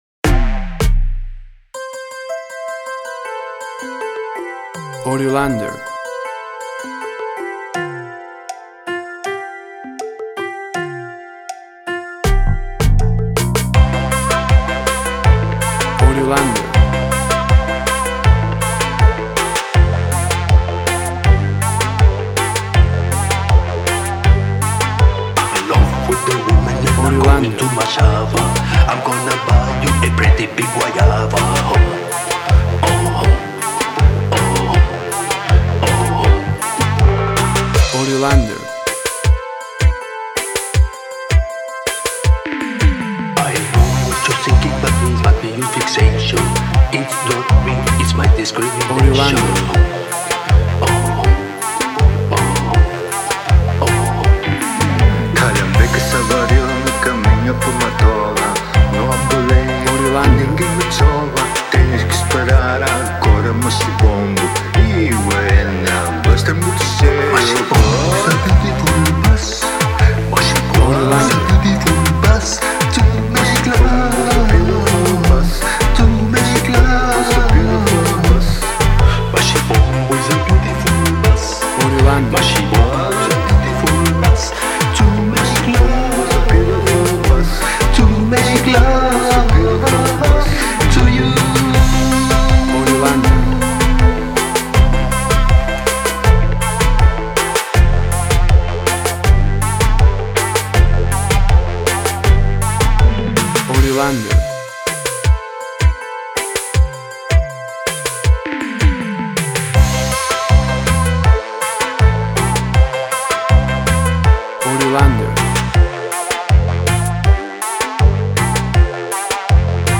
afro pop
Tempo (BPM): 81